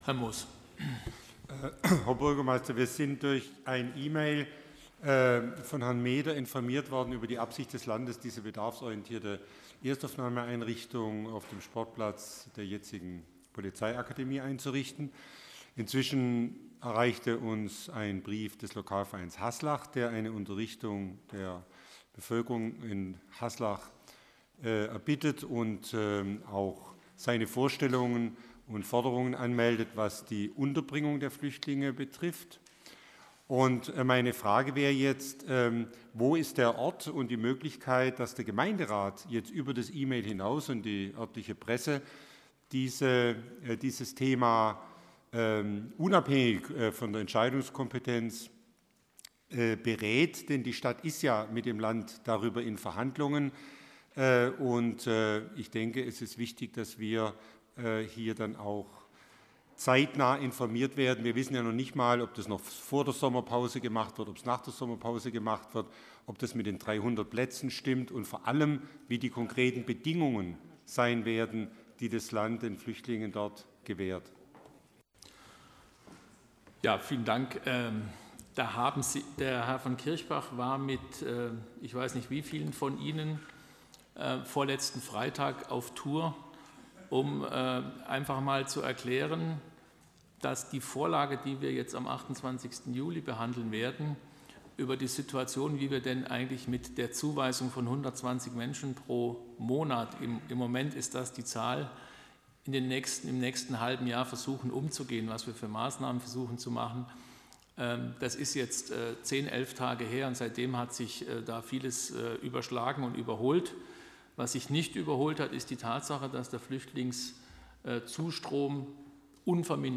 Gespräch über die Einrichtung der Landeserstaufnahemestelle für oder ehrlicher gesagt gegen Flüchtlinge. In Baden-Württemberg werden die Kapazitäten gerade massiv ausgebaut.